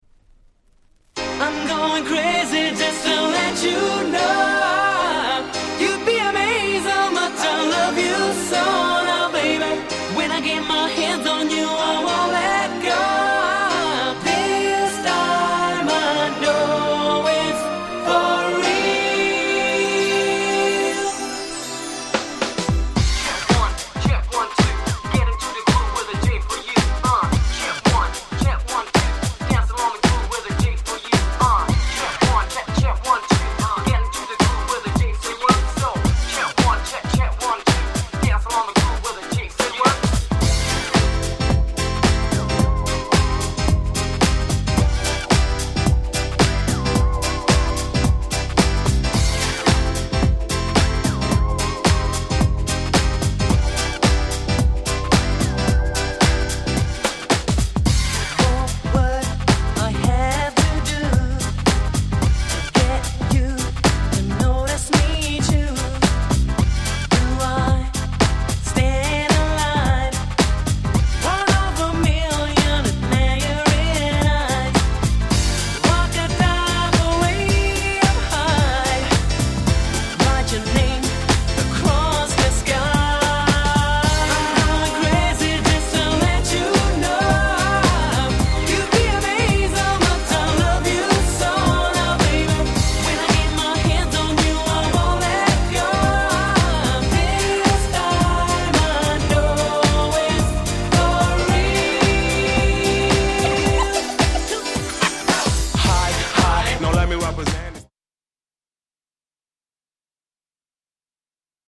人気キャッチーR&Bコンピレーション！！